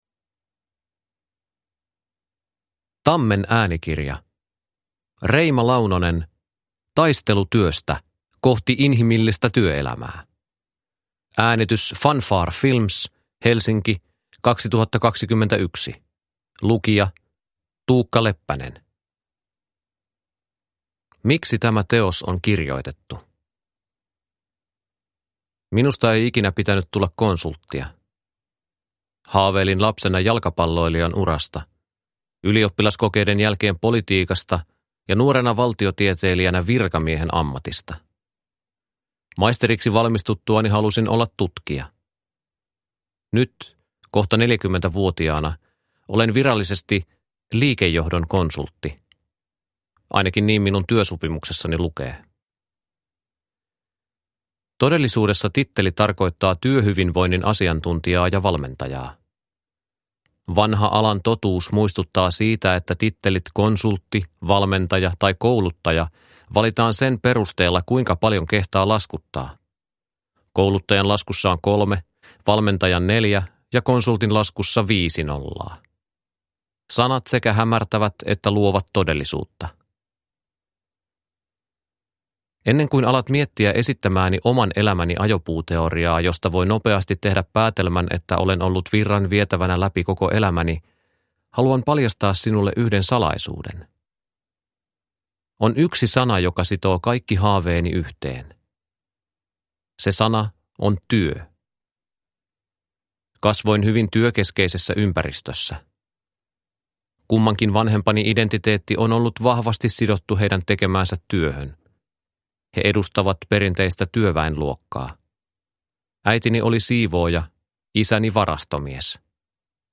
Taistelu työstä – Ljudbok – Laddas ner